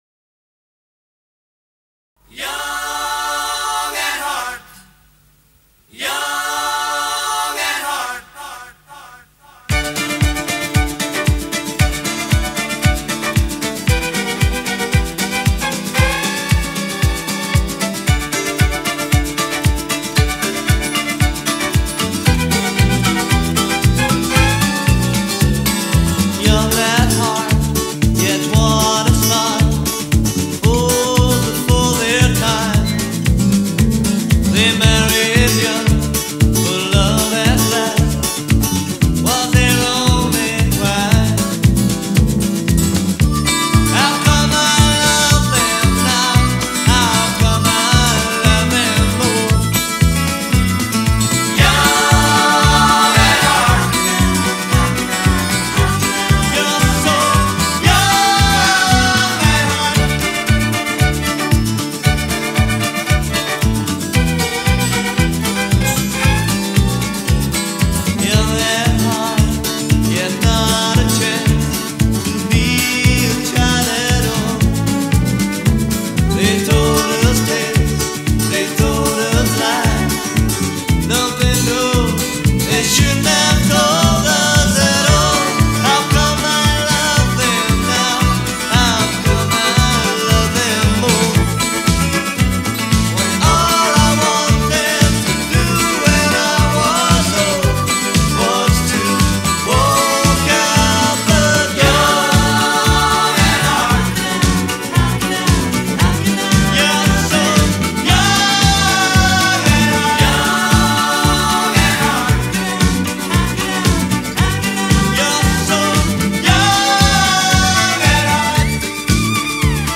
melancholic countrified hoedown